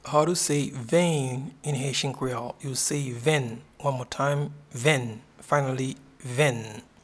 Pronunciation and Transcript:
Vein-in-Haitian-Creole-Venn.mp3